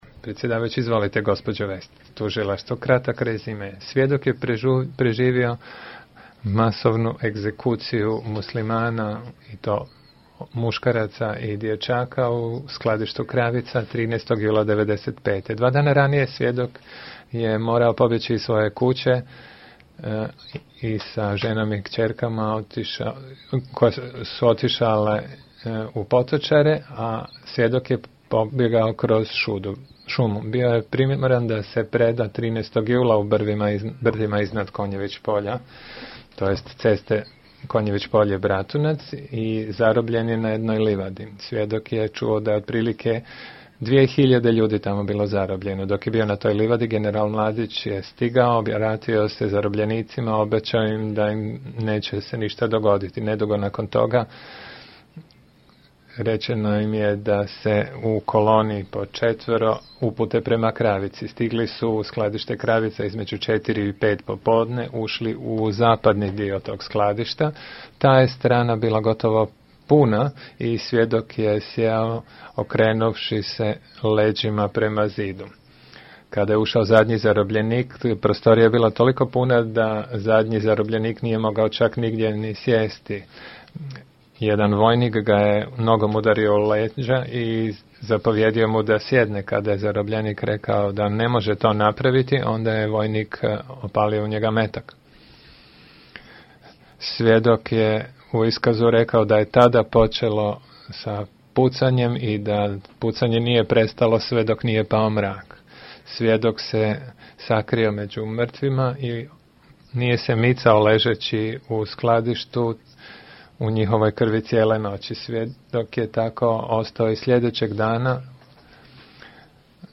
Sažetak iskaza zaštićenog svjedoka pročitan od strane tužiteljstva